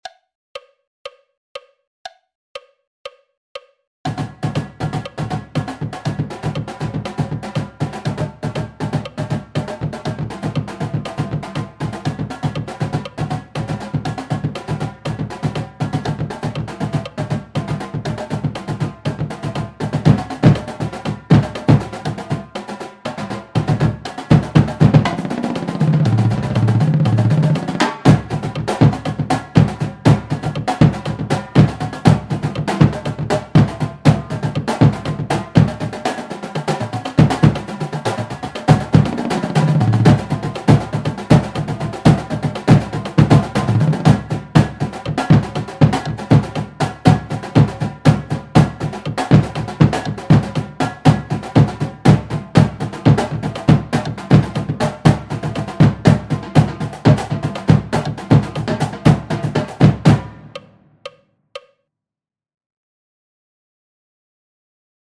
Snare Exercises
Bounce (Double Strokes) /